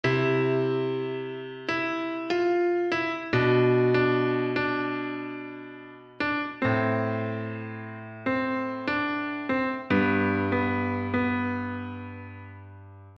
标签： 器乐 钢琴
声道立体声